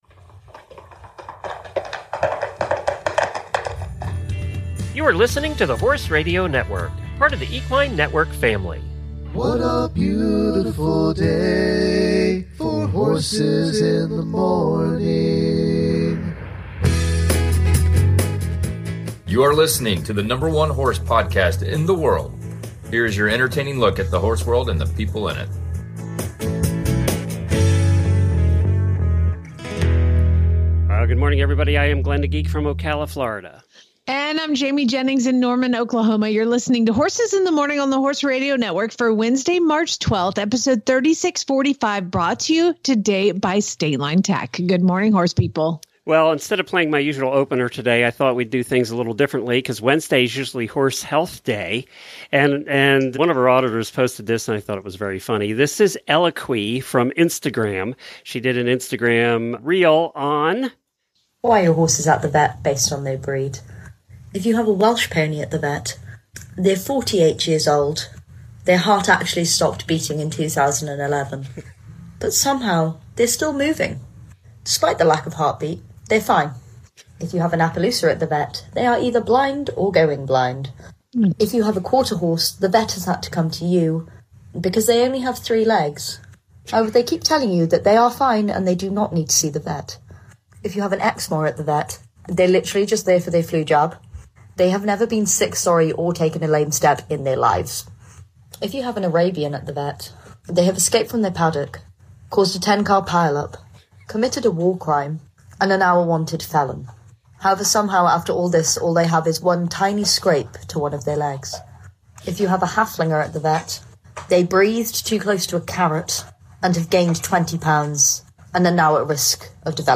Auditor Post Show: We have a chat with Sesame AI and ask him 10 non horse person questions.